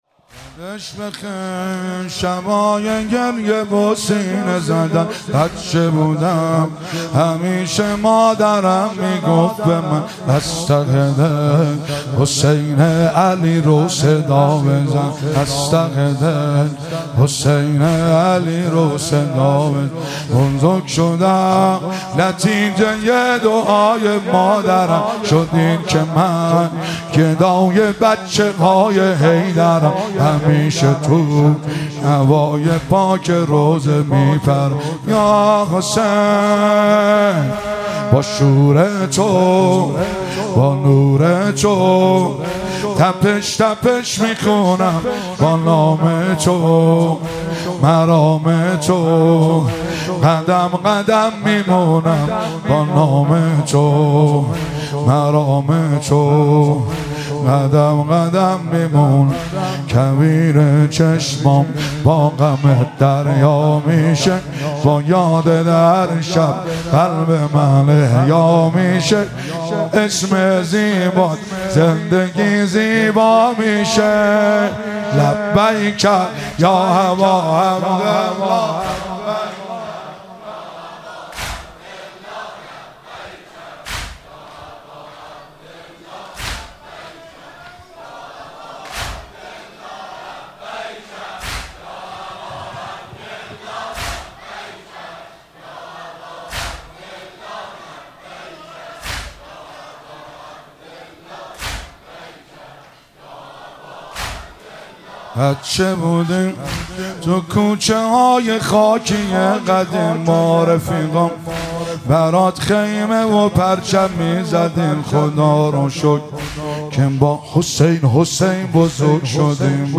مراسم عزاداری شب تاسوعا محرم 1445
واحدتند- یادش بخیر شبای گریه و سینه زدن